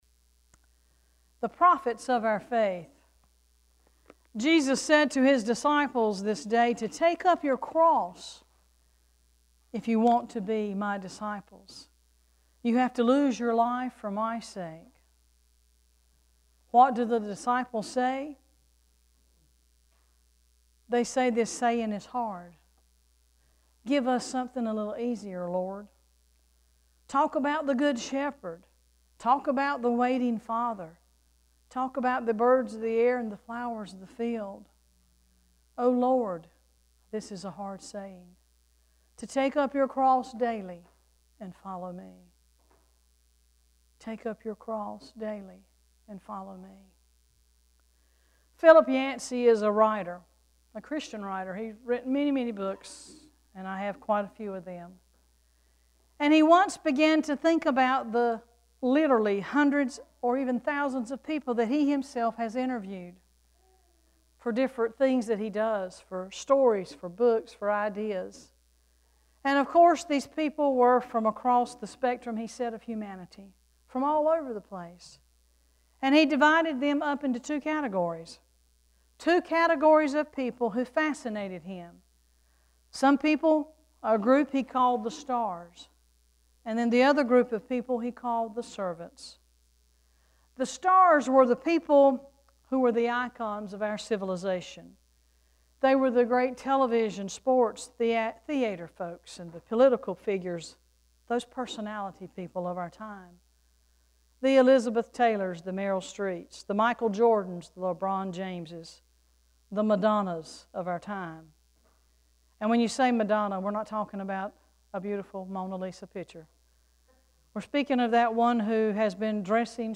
Worship Service 3-1-15: The Profits of our Faith
3-1-sermon.mp3